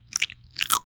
Bodily Sound Effects - Free AI Generator & Downloads
realistic-sound-of-eating-cdy5zicq.wav